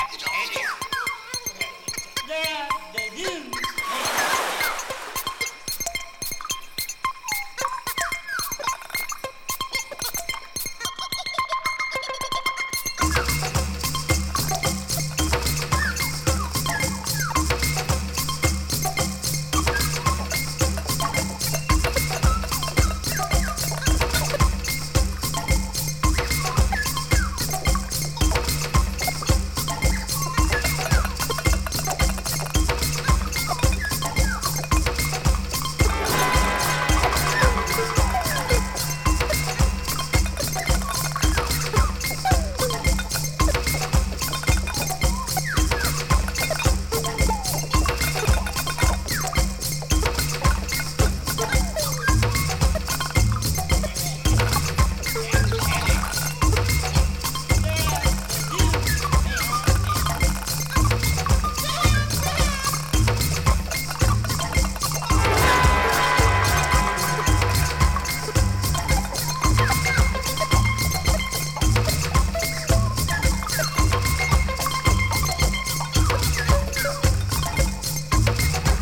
モンドでキッチュなエレクトロビート